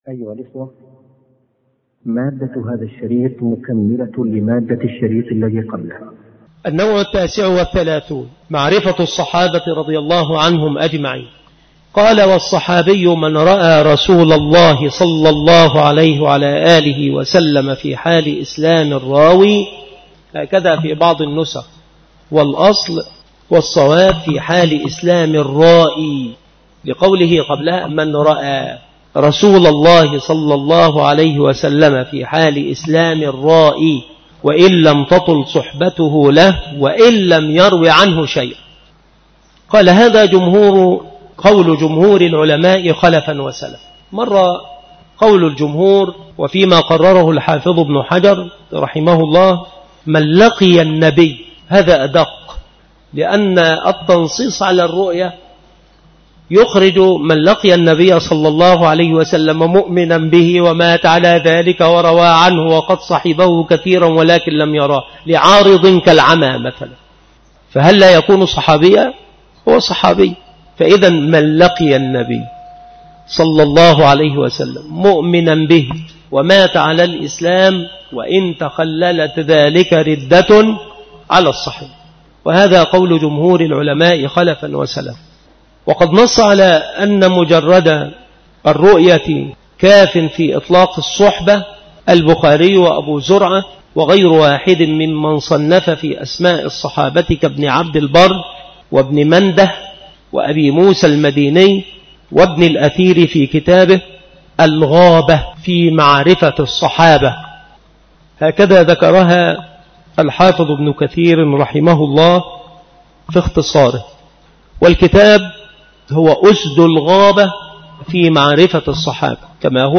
مكان إلقاء هذه المحاضرة بالمسجد الشرقي بسبك الأحد - أشمون - محافظة المنوفية - مصر عناصر المحاضرة : النوع التاسع والثلاثون: معرفة الصحابة رضي الله عنهم.